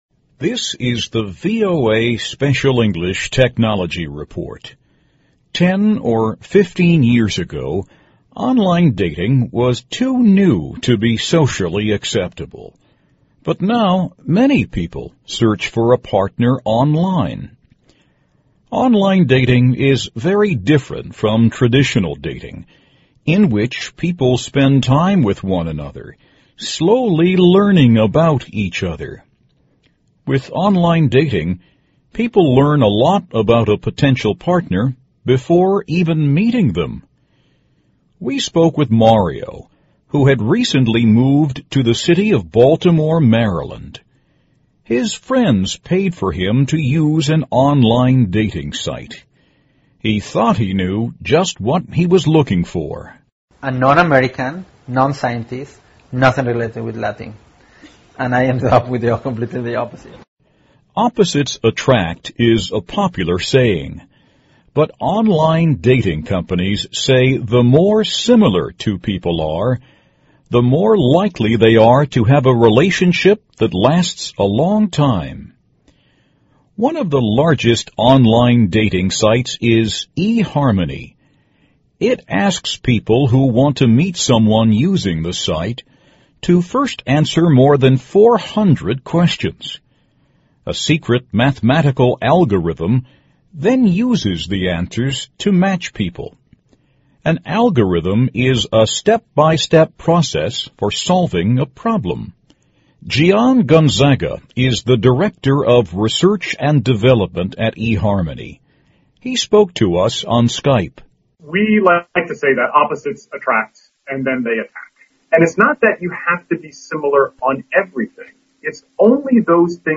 VOA慢速英语2012 Technology Report - Finding the Right Match With Online Dating 听力文件下载—在线英语听力室